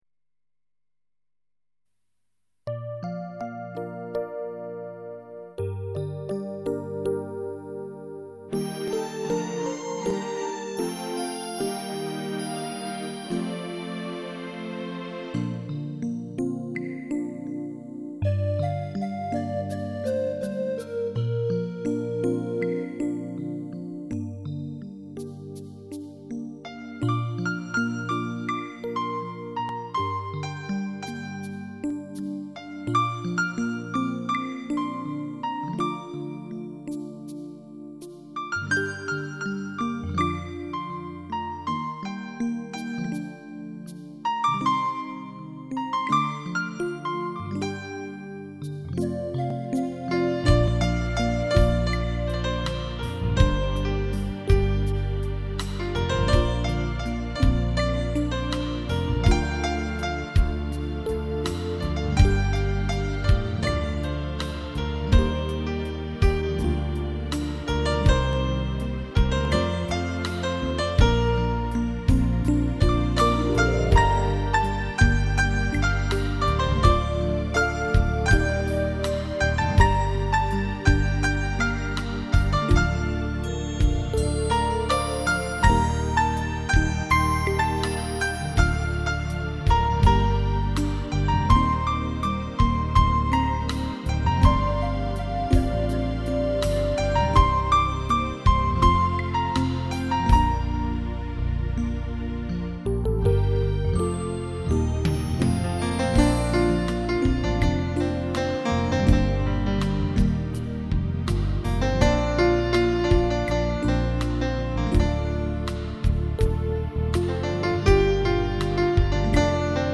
很甜美的音乐~！